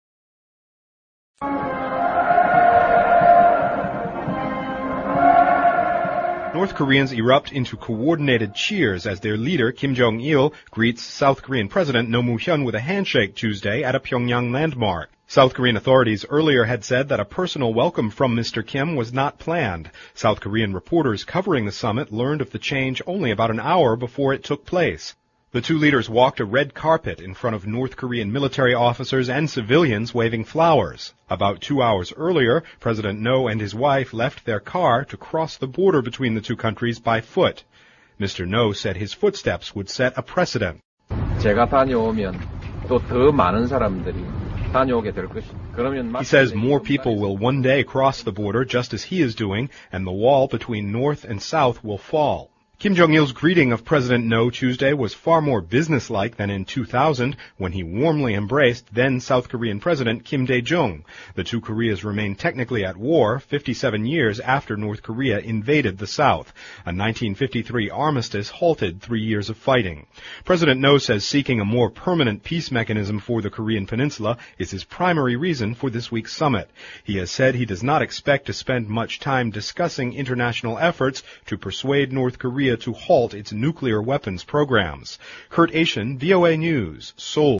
VOA常速英语2007-North Korean Leader Personally Greets South Kore 听力文件下载—在线英语听力室